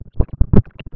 Nota realizada al Presidente Dr. Jorge Batlle Ibáñez en el Cine Plaza,
luego de la entrega de los "Premios a los Deportistas del Año 2003".